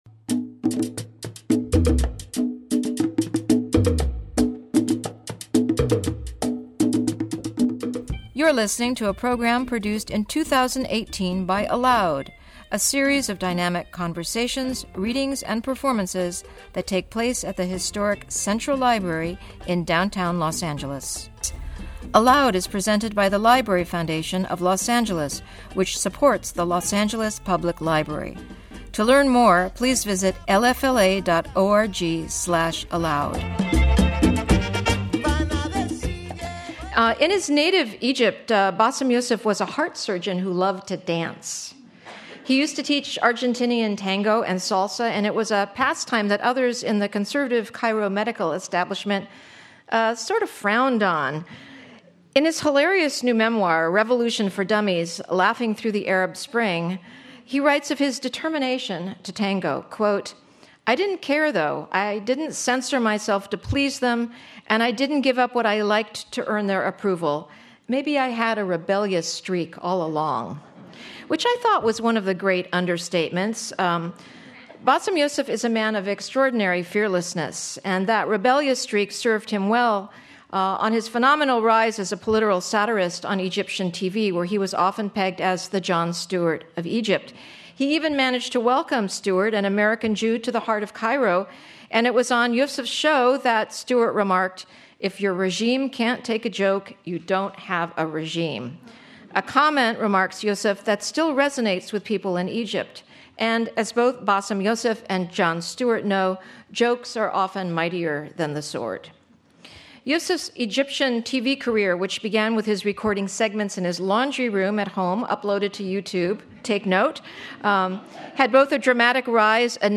Bassem Youssef In Conversation With Kelly McEvers